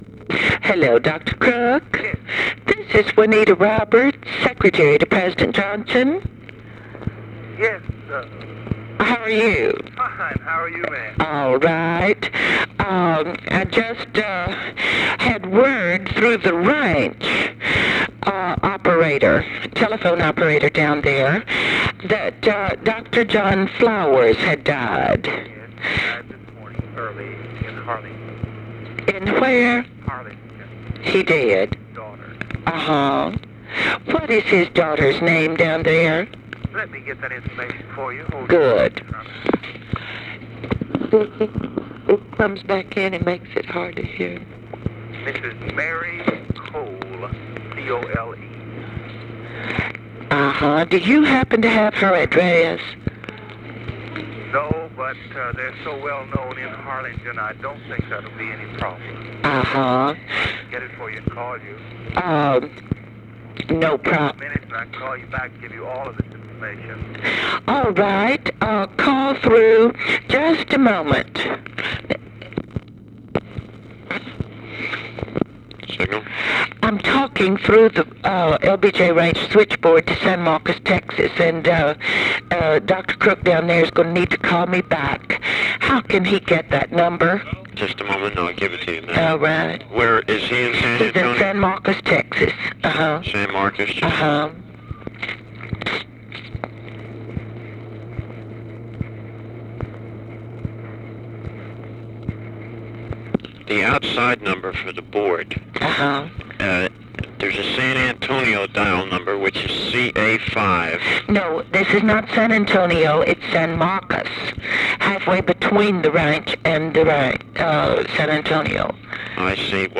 Conversation with WILLIAM CROOK and SIGNAL CORPS OPERATOR
Secret White House Tapes